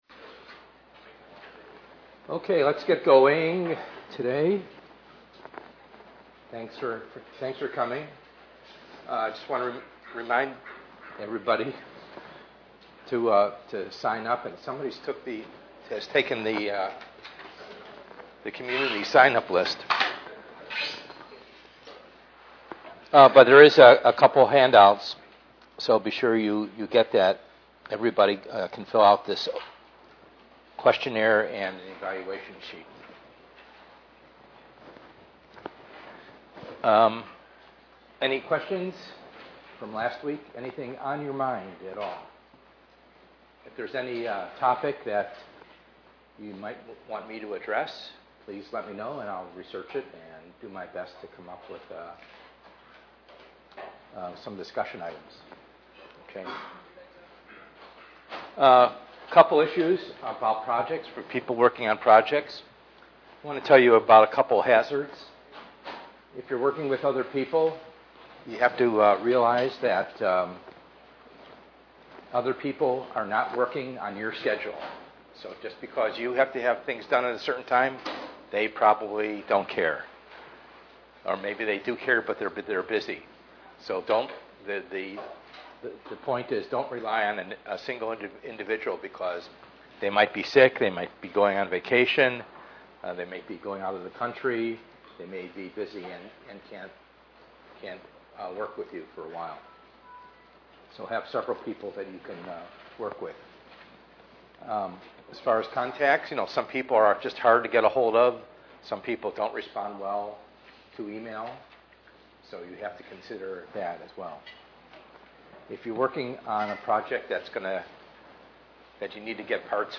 ENGR110/210: Perspectives in Assistive Technology - Lecture 04a